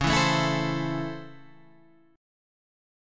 DbmM7#5 chord